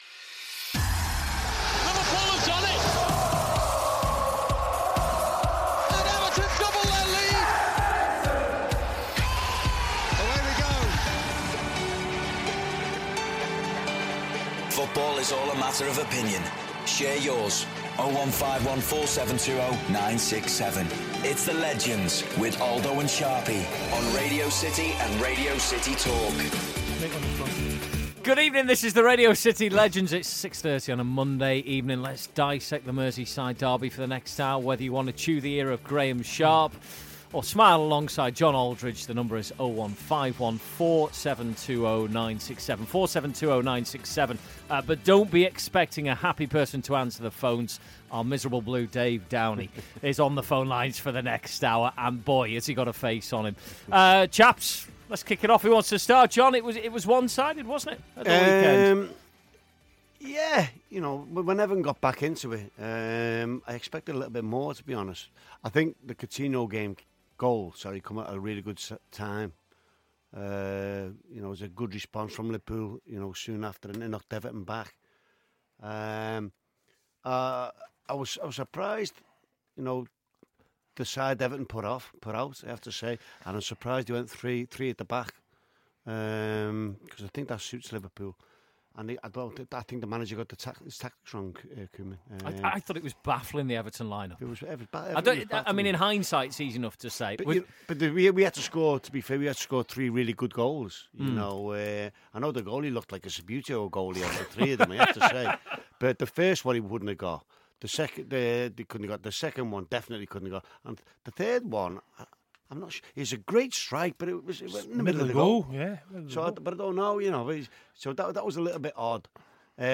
Graeme Sharp and John Aldridge take your calls and debate all the fallout from the Merseyside derby.